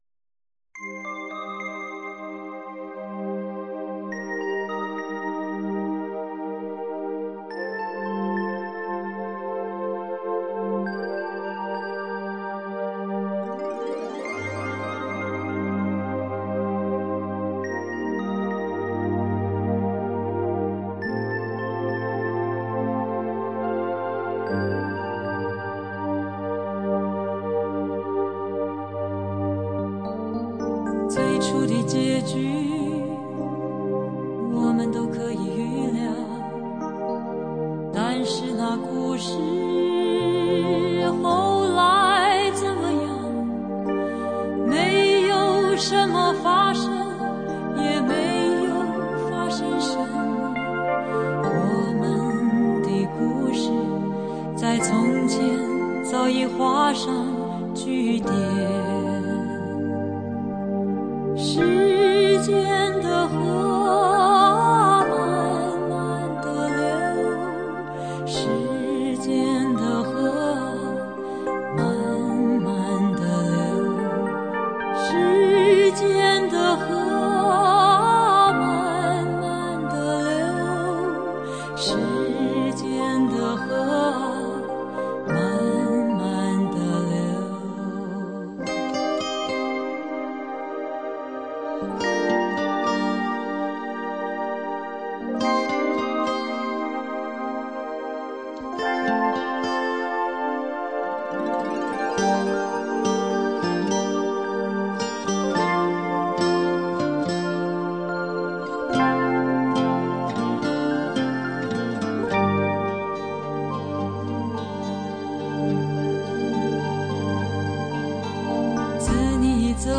缓慢、出世、清新再加上一点追忆时淡淡的愁